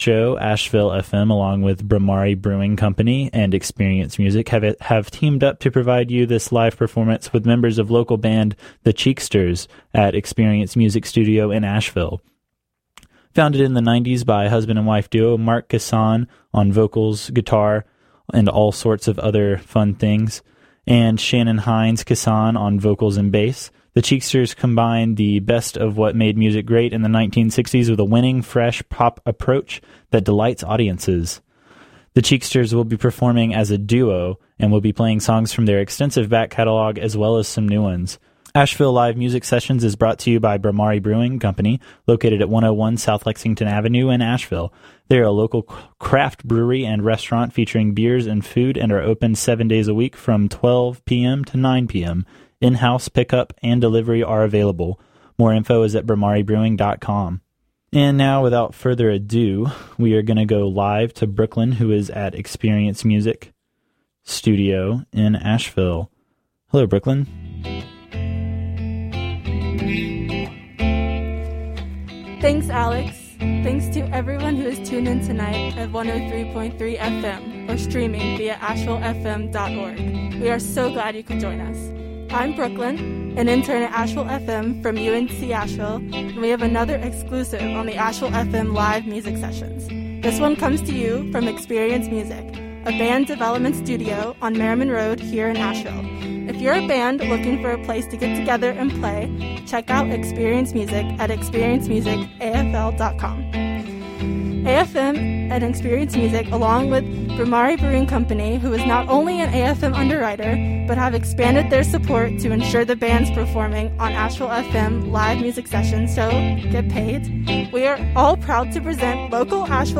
live performance with members of local band
guitar, vocals, allsorts
vocals, bass